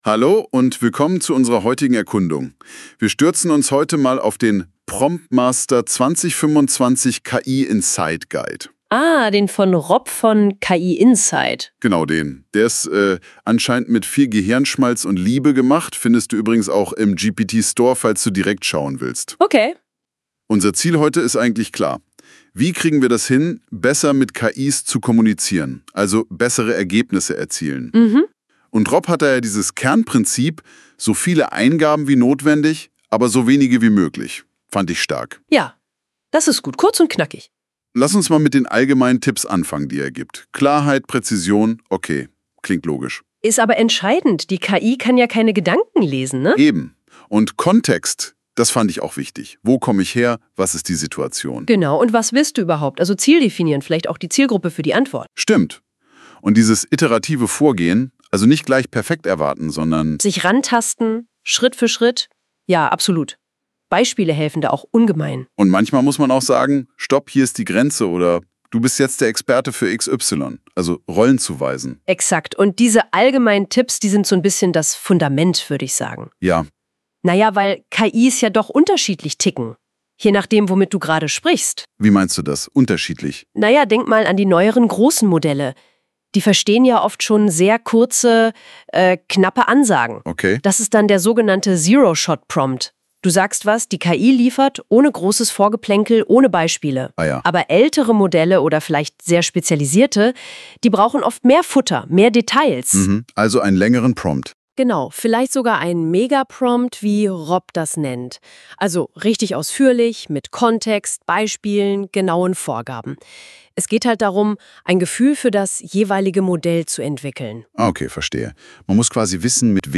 100 % KI erzeugter Podcast